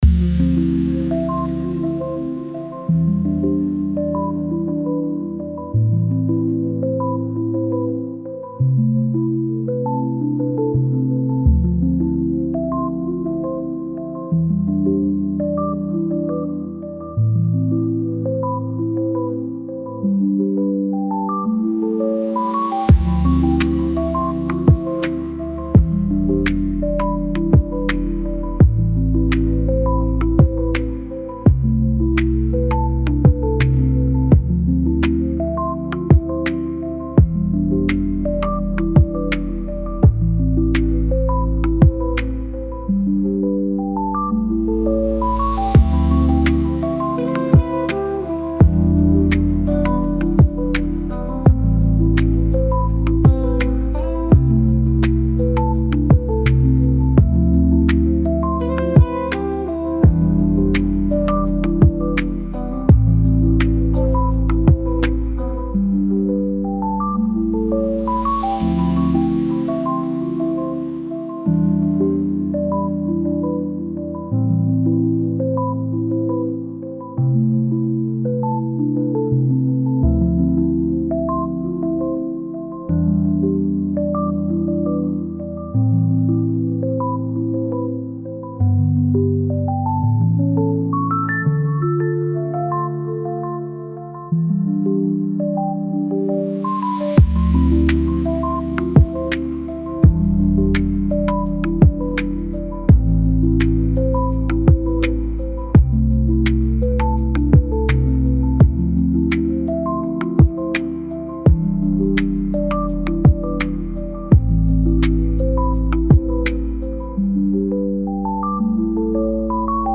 nugui-ambient-sound.mp3